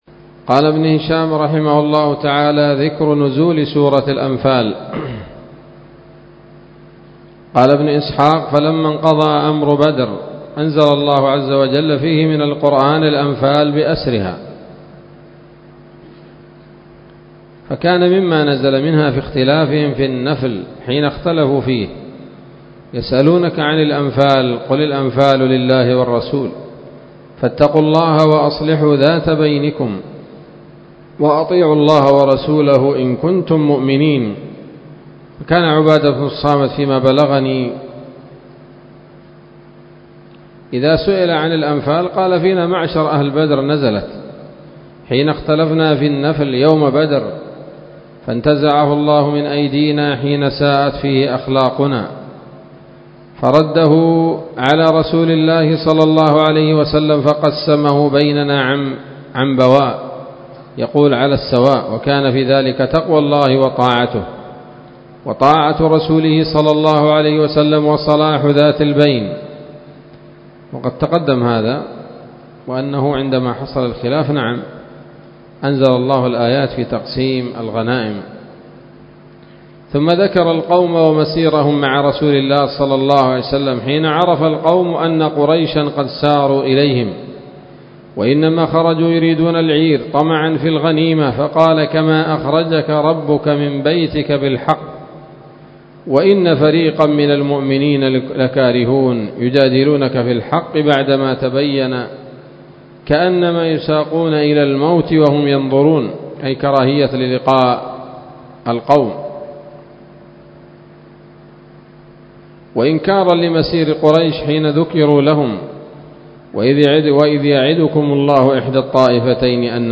الدرس الثلاثون بعد المائة من التعليق على كتاب السيرة النبوية لابن هشام